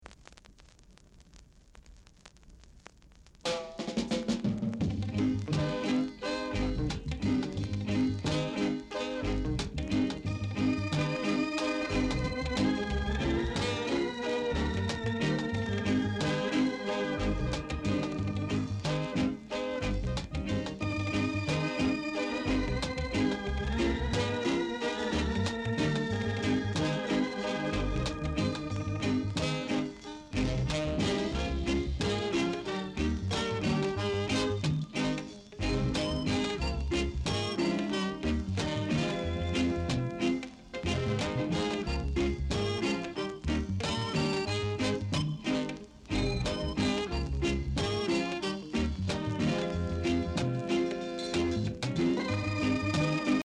R.Steady Vocal Group, Inst